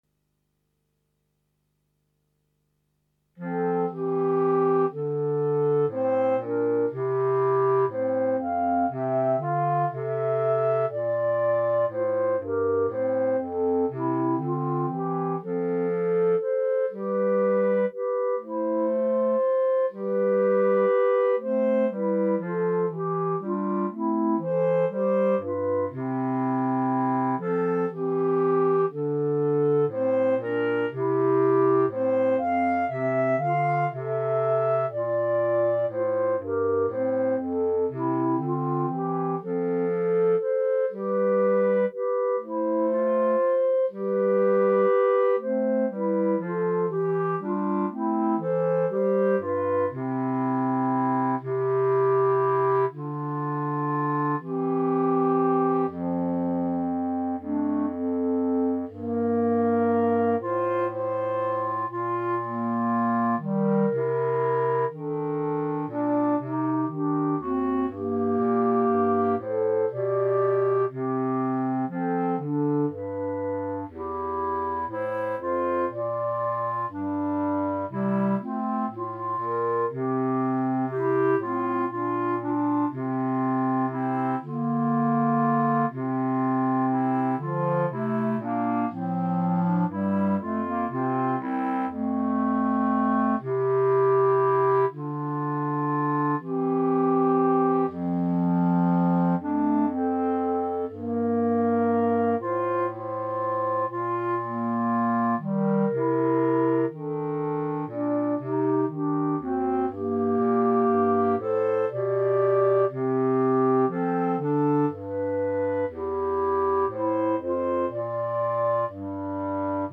Right click to download Hornpipe minus Clarinet 3